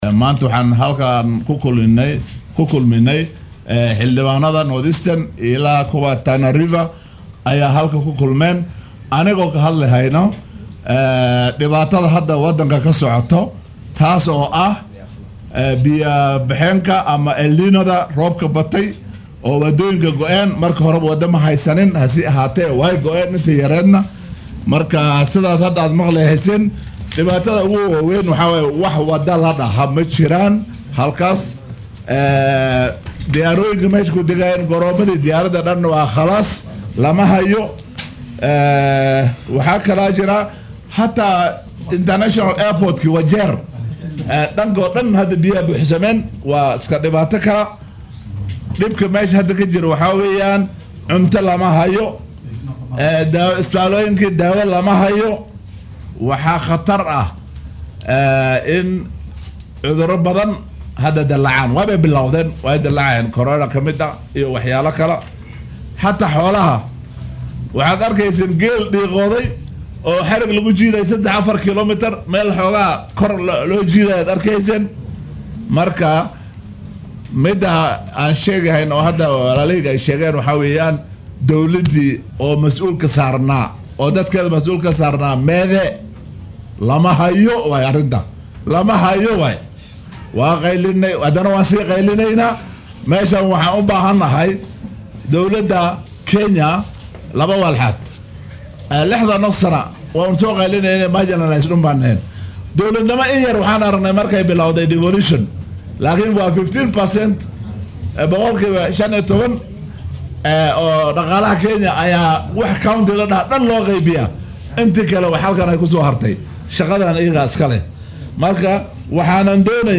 Mudanayaasha laga soo doortay deegaannada hoos tago ismaamullada gobolka Waqooyi Bari ee Garissa, Wajeer iyo Mandera oo maanta shir jaraaid qabtay ayaa dowladda dhexe ugu baaqay inay daadadka wadanka ku dhuftay u aqoonsato musiibo qaran.
Xildhibaan Aadan Xaaji Yuusuf oo laga soo doortay deegaanka Galbeedka Mandera oo warbaahinta Star faahfaahin ka siiyay kulankooda ayaa ka hadlay saameynta ay daadadka ku yeesheen gobolka Waqooyi Bari.